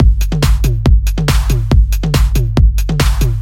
标签： 140 bpm Electro Loops Synth Loops 1.18 MB wav Key : Unknown
声道立体声